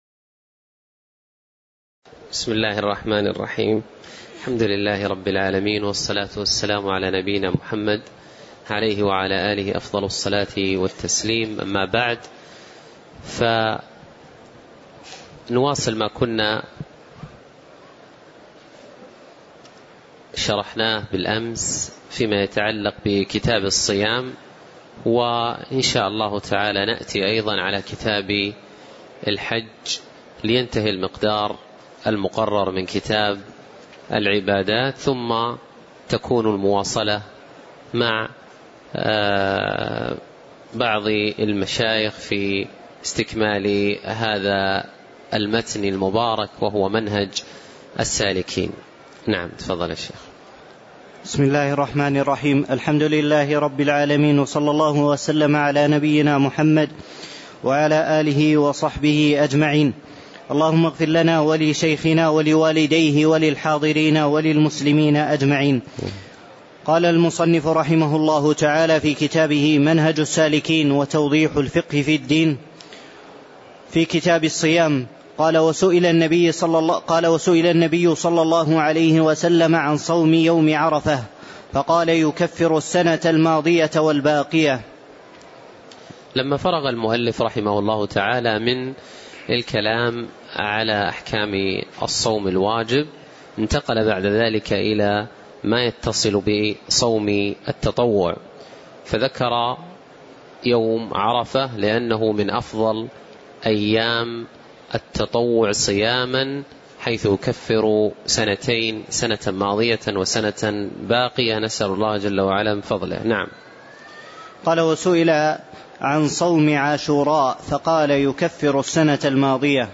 تاريخ النشر ١٨ شوال ١٤٣٧ هـ المكان: المسجد النبوي الشيخ